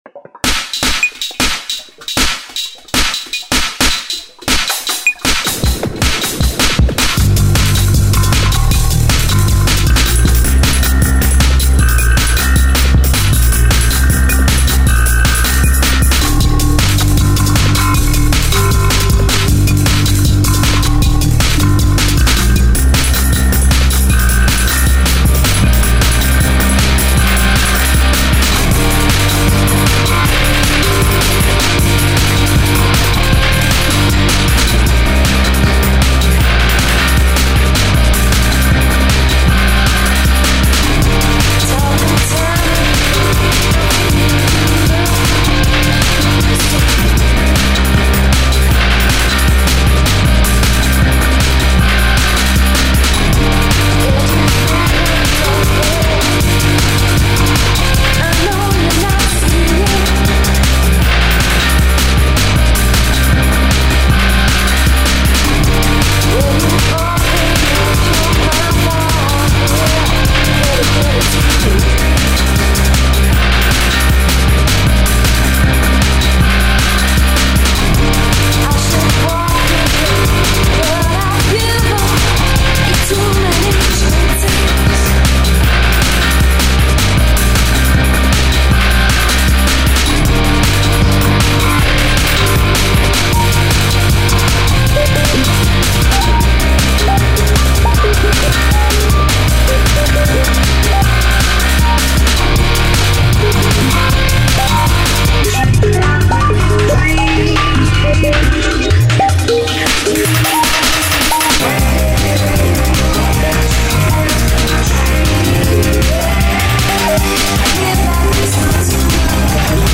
dance/electronic
158bpm, female vocal, guitar and synth rock thang.
Breaks & beats
Rock & Roll